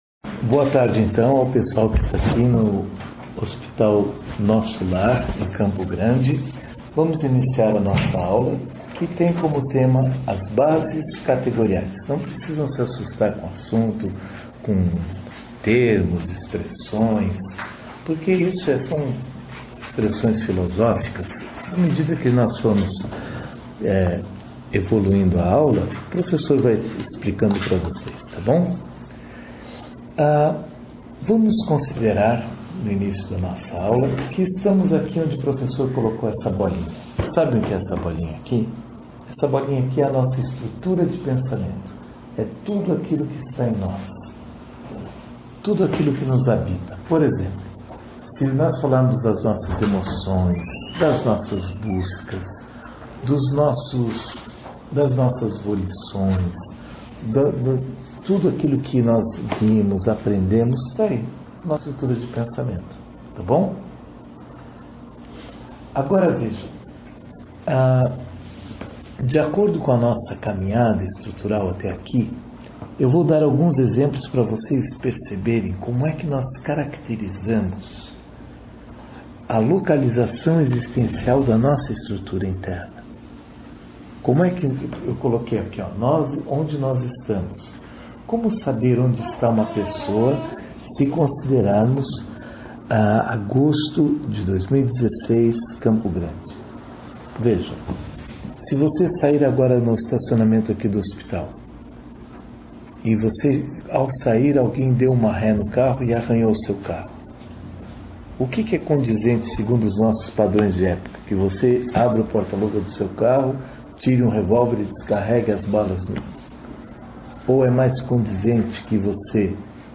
workshop sobre Filosofia Cl�nica
no Hospital Nosso Lar, em Campo Grande